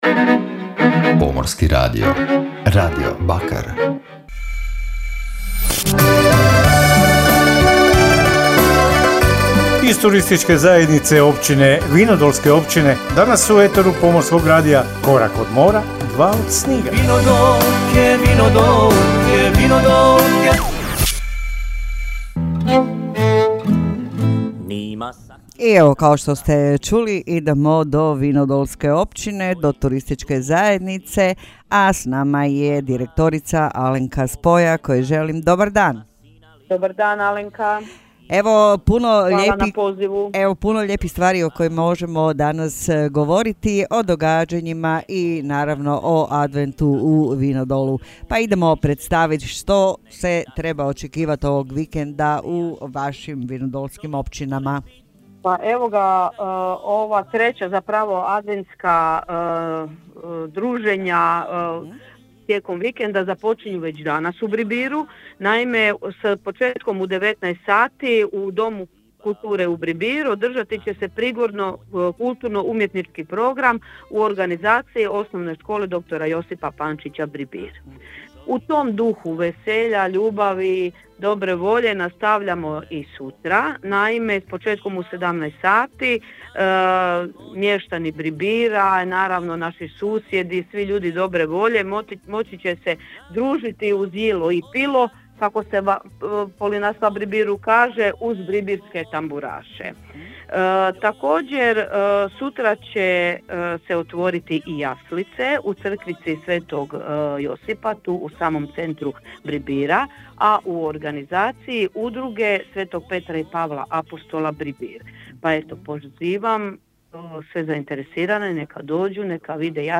[INTERVJU]: TREĆI ADVENTSKI VIKEND U VINODOLU!